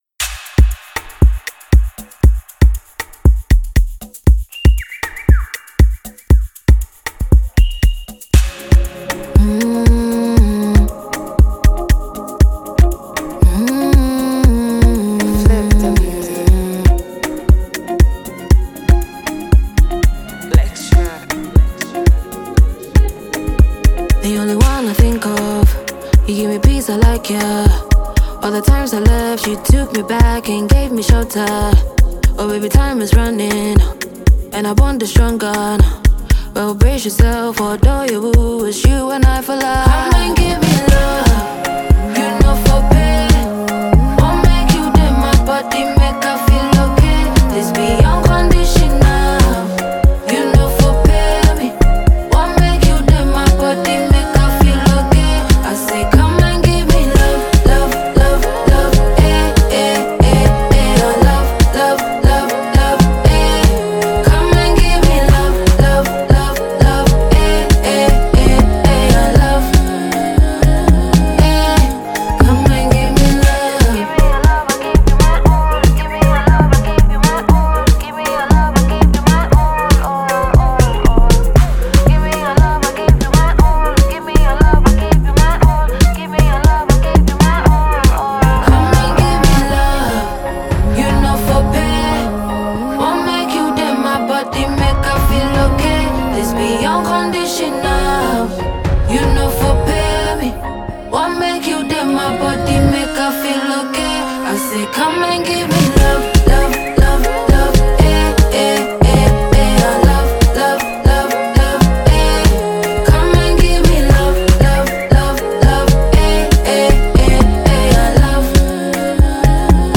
a super-talented Ghanaian female singer.